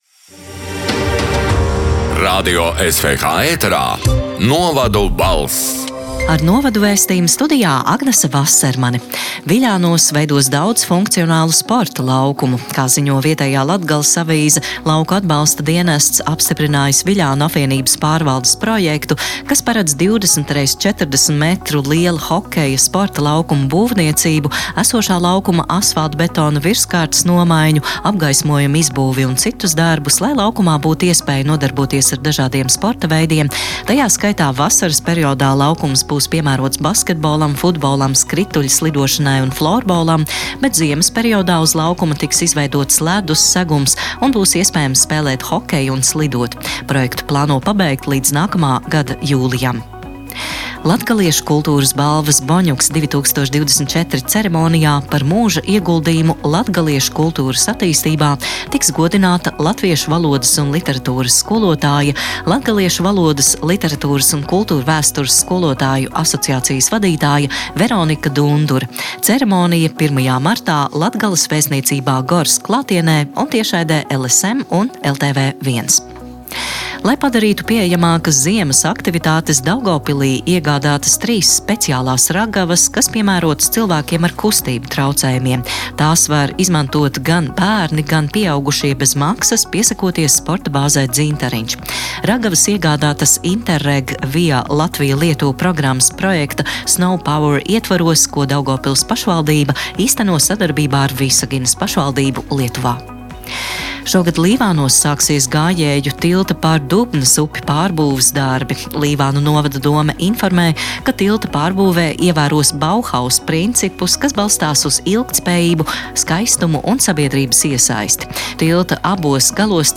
“Novadu balss” 21. februāra ziņu raidījuma ieraksts: